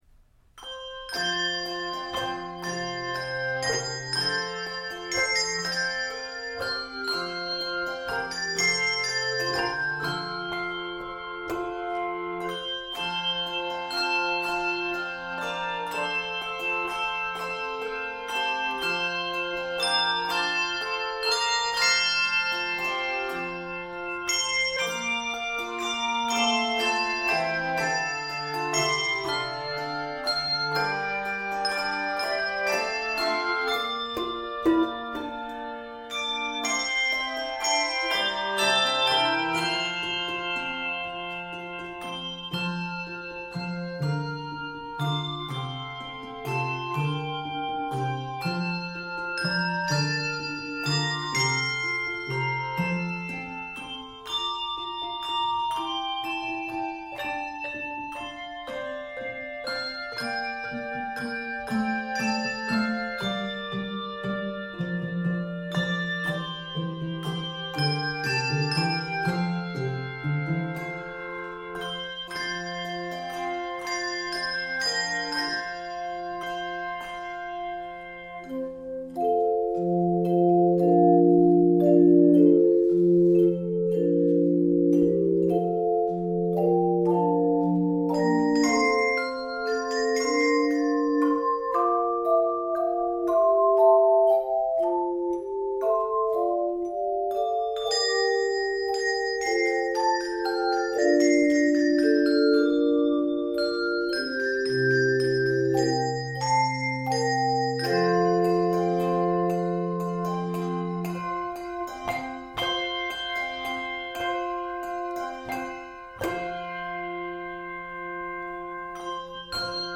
Celtic-style melody
Key of F Major.